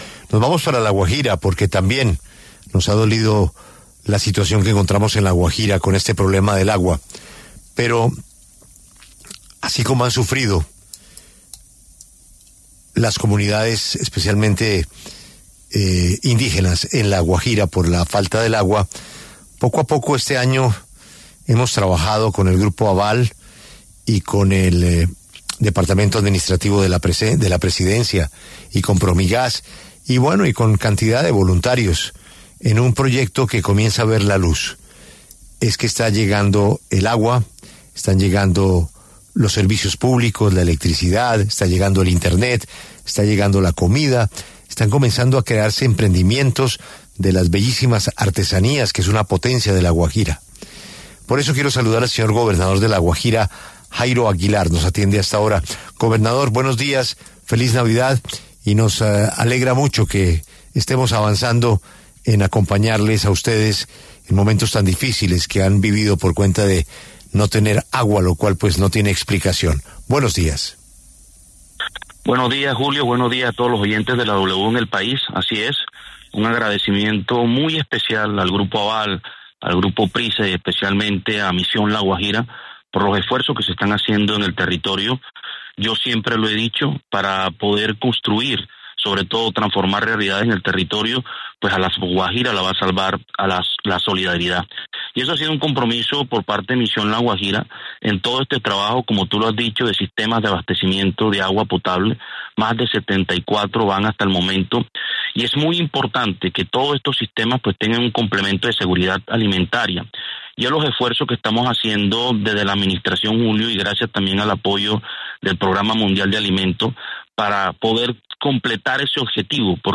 En diálogo con La W, el gobernador de La Guajira, Jairo Aguilar, hizo un balance sobre los esfuerzos para transformar el departamento.